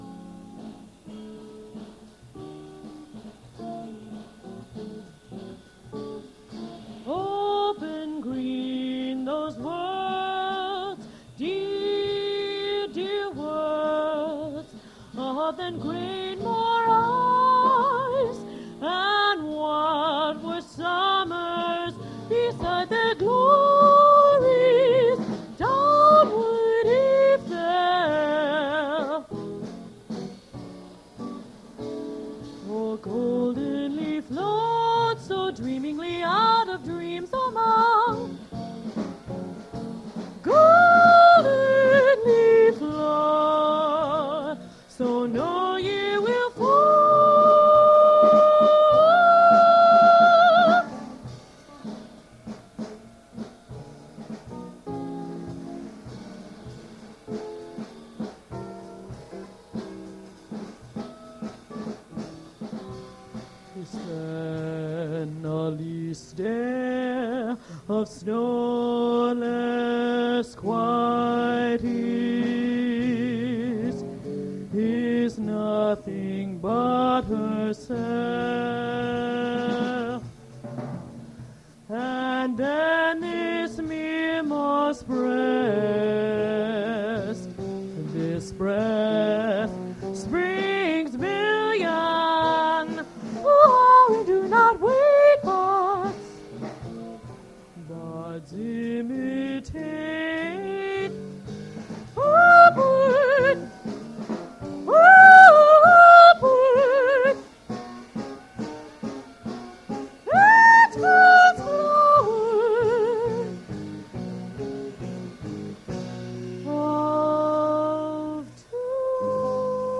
Poetry reading and music
reading their poetry at Duff's Restaurant
generated from original audio cassette
recording starts from a song; recording quality is not good (very quiet)